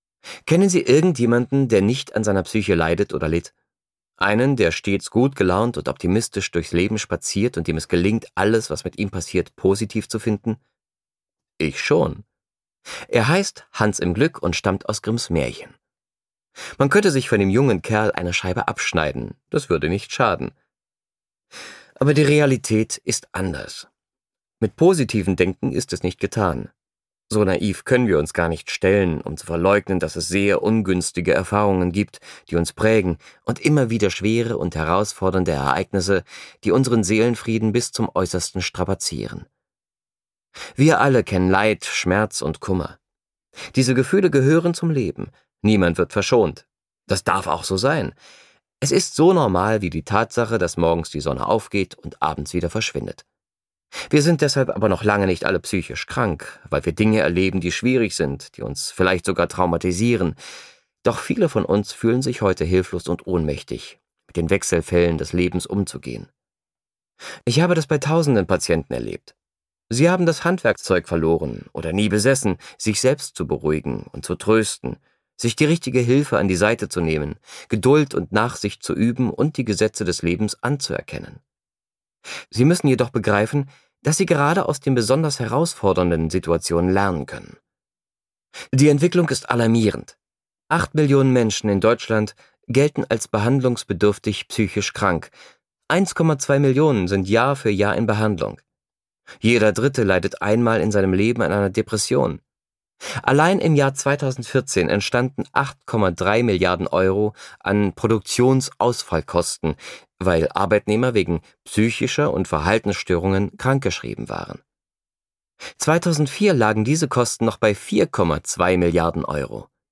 Schlagworte Angstbewältigung • Burnout • Depressionen • Hörbuch ungekürzt • Macht der Gefühle • Psychosomatische Erkrankungen • Sachbuch Hörbuch • Sachbuch Psychologie • Seelische Gesundheit • Trauma